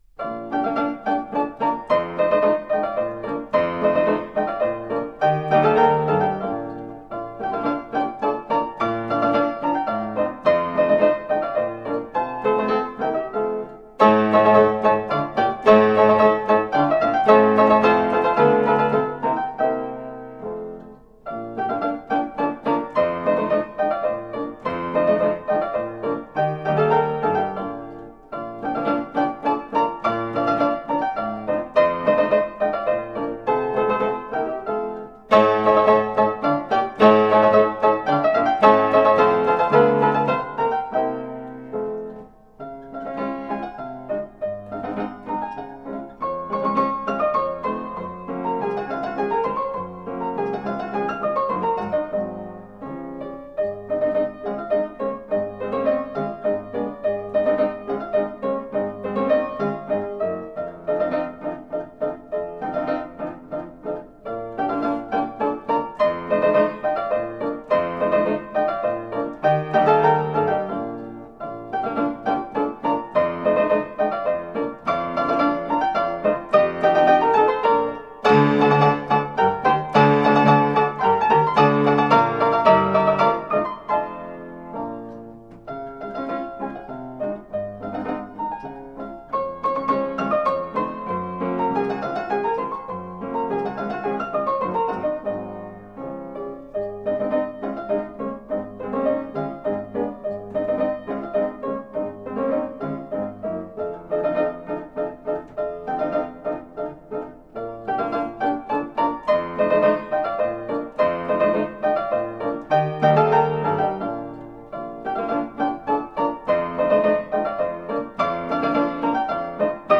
Fortepiano music from the early 1800s.